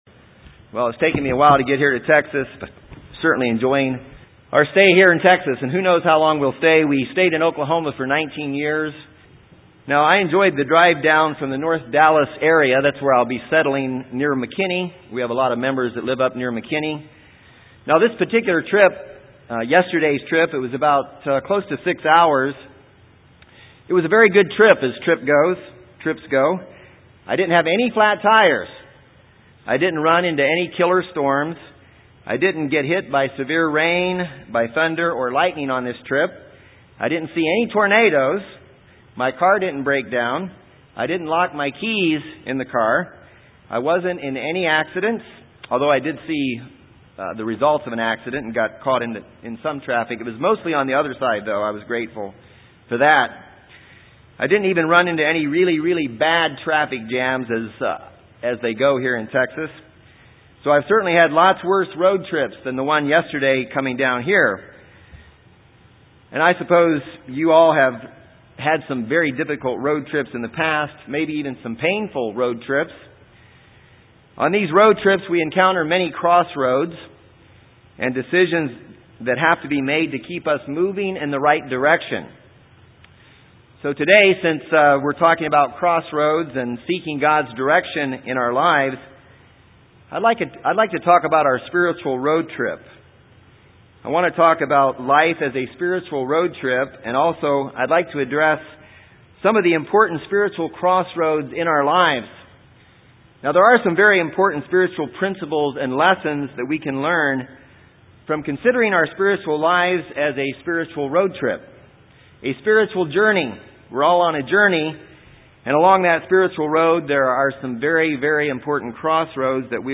Our spiritual road trip through the life God has planned for us can be filled with choices we must make to stay on the road God wants us to be on. In this sermon we will learn of several guidelines for having a successful spiritual road trip.